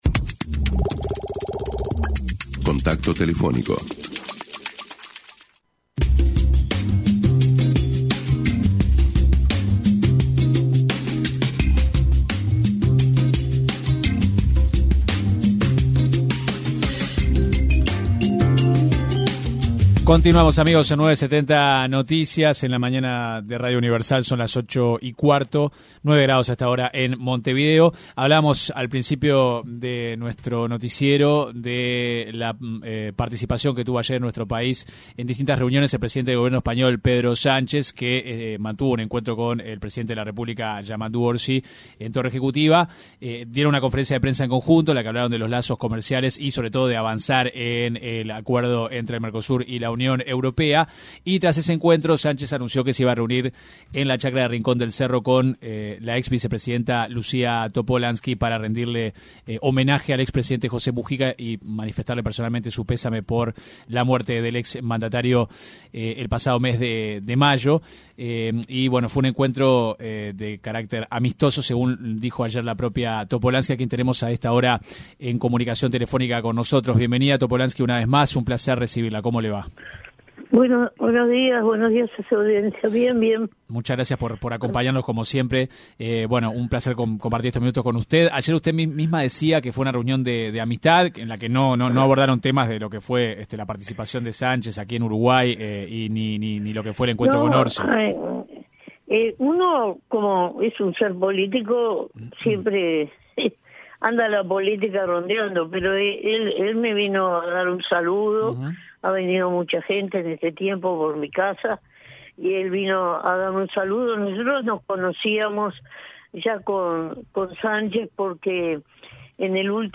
La exvicepresidenta de la República Lucía Topolansky, apuntó en diálogo con 970 Noticias, contra el exsubsecretario de Ambiente, Gerardo Amarilla tras sus dichos sobre la decisión del gobierno de dar marcha atrás con el Proyecto Arazatí.